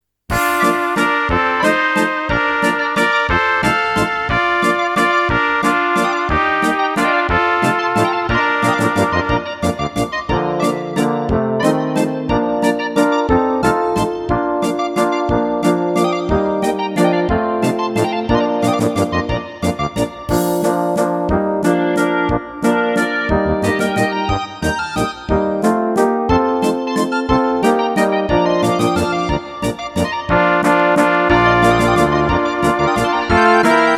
Rubrika: Národní, lidové, dechovka
- valčík